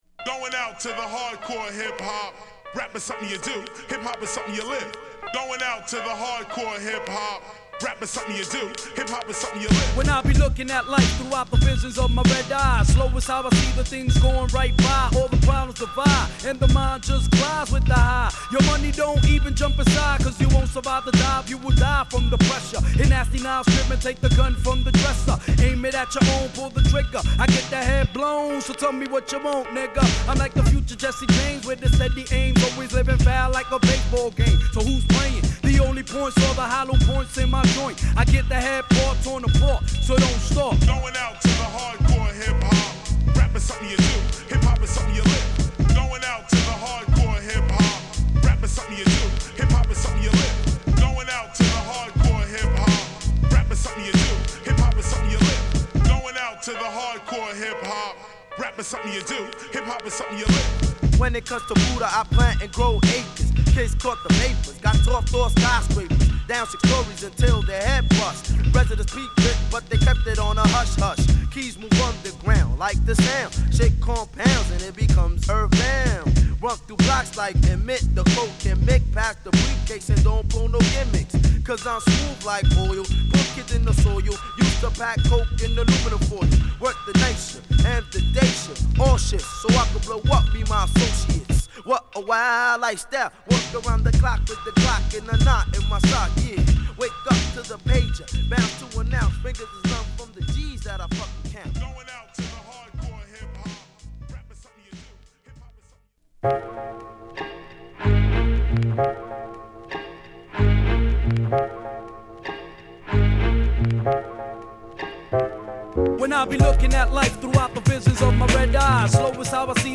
極太のドラムを打ち付けた男臭さ全開のビートに哀愁のピアノを絡めたドープRemix！